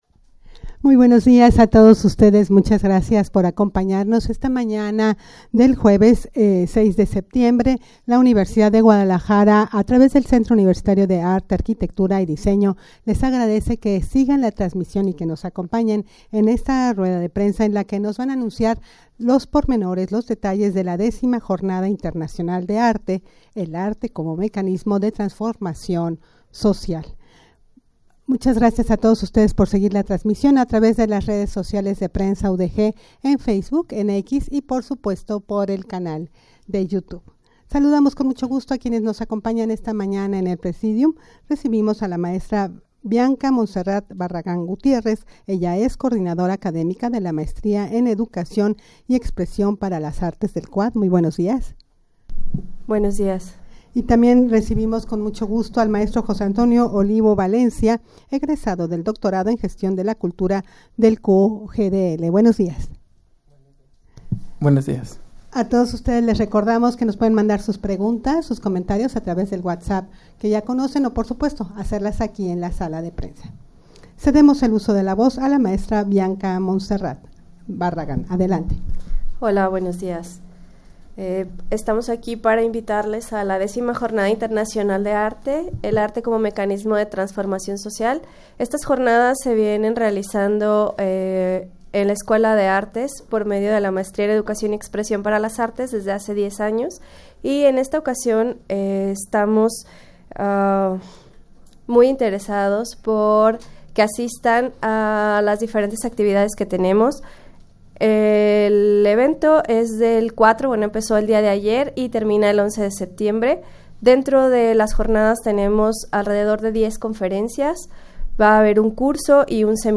Audio de la Rueda de Prensa
rueda-de-prensa-para-anunciar-la-x-jornada-internacional-de-arte-el-arte-como-mecanismo-de-transformacion-social.mp3